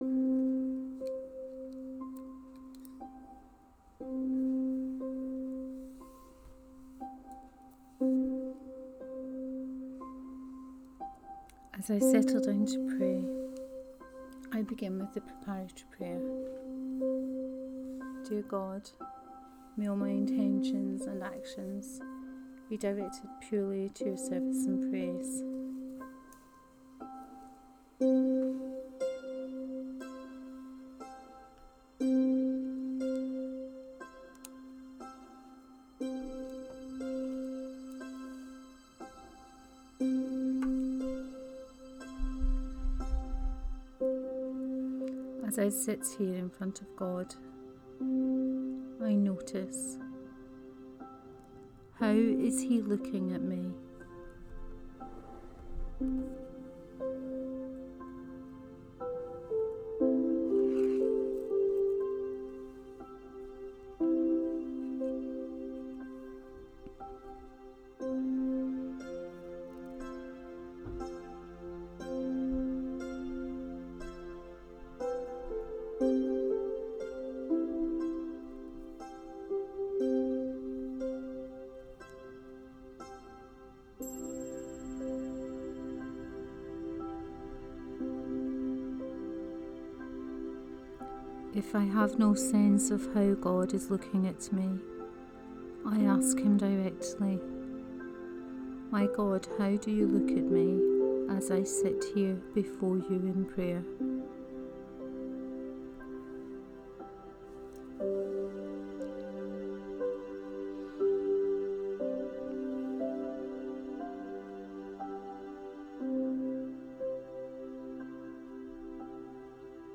Seventh Sunday in Ordinary Time, Cycle A
Here, as stated before, it is my intention to draw from the forthcoming Sunday liturgy and to offer a guided prayer on one of the pieces of scripture in the same way that we have been doing in Exploring Personal Prayer. I do not intend to offer any reflections on the scripture.
praying-with-images-1-corinthians-3.m4a